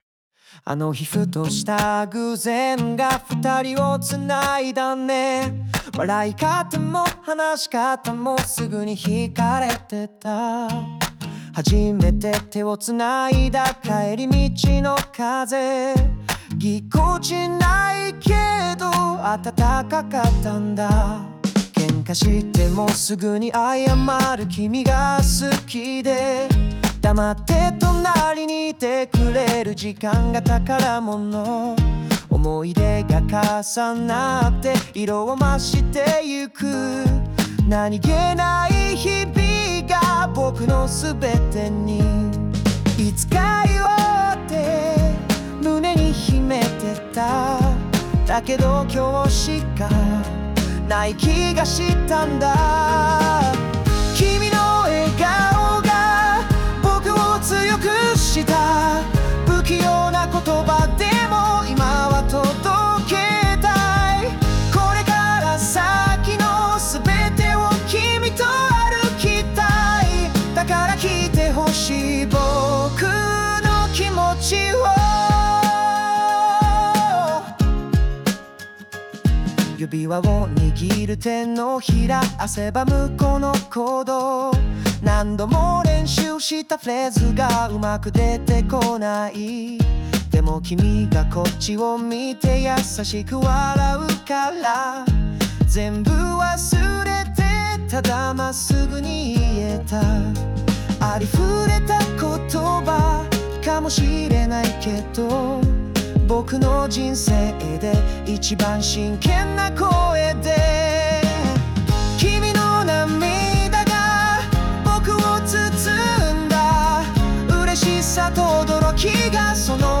邦楽男性ボーカル著作権フリーBGM ボーカル
著作権フリーオリジナルBGMです。
男性ボーカル（邦楽・日本語）曲です。